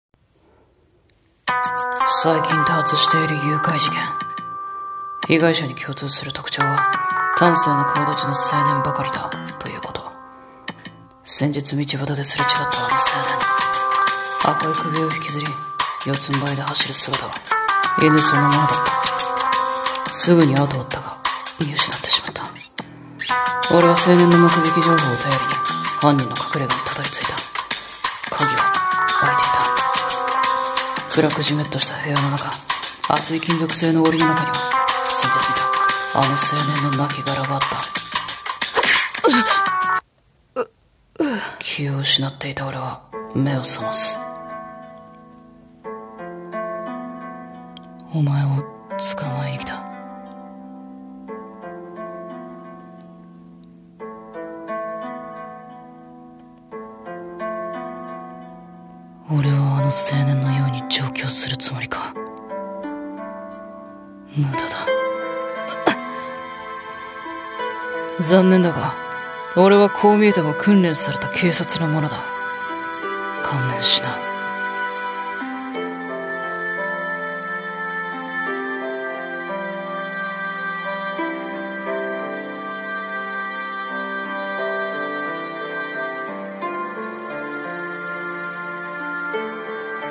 声劇 台本❶❾「 夢 檻 」〜僕の罪〜